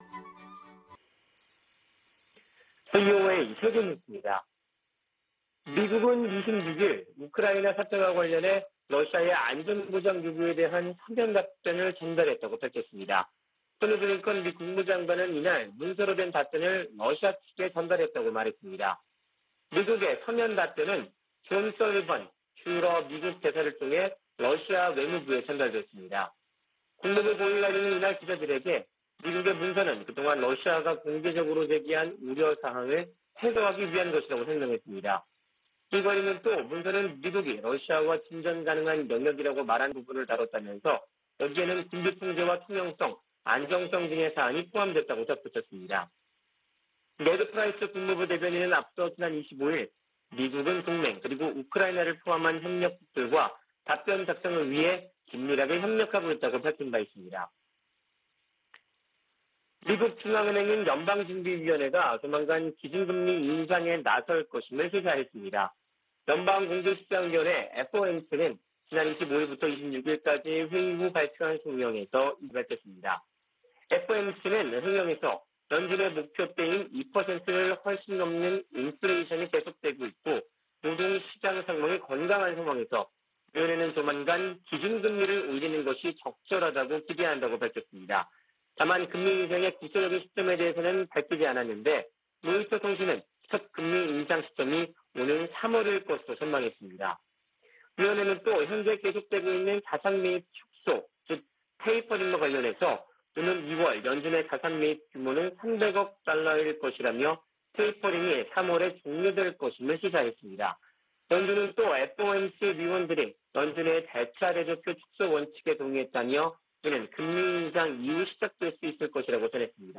VOA 한국어 아침 뉴스 프로그램 '워싱턴 뉴스 광장' 2021년 1월 27일 방송입니다. 미 국무부는 북한이 순항미사일 2발을 발사한 것과 관련, 여전히 평가 중이라고 밝히고, 대북 정책 목표는 여전히 한반도의 완전한 비핵화라고 확인했습니다.